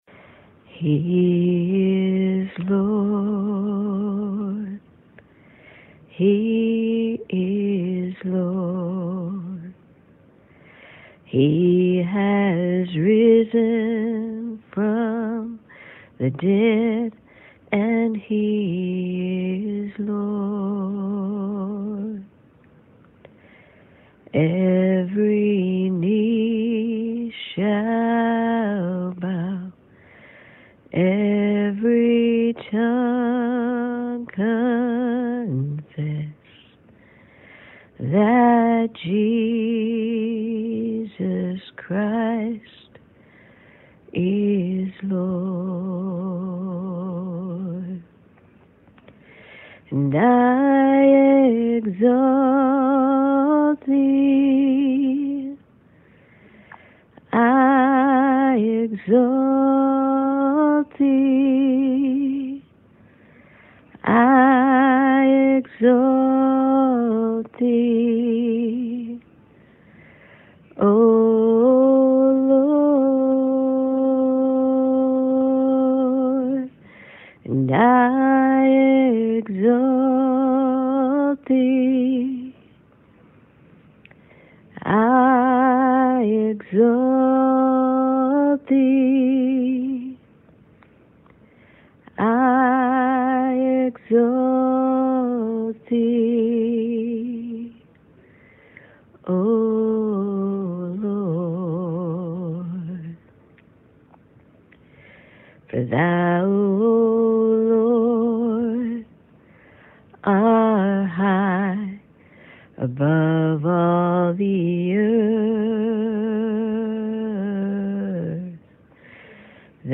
Audio only, below, Songs "He is Lord" and "I Exalt Thee"
Service Type: Sunday Morning